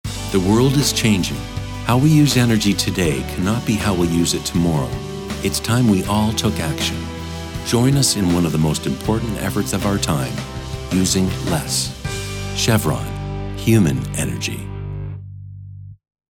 Sprechprobe: Industrie (Muttersprache):
Warm, conversational, friendly voice, but versatile enough to deliver corporate, authoritative, announcer like reads.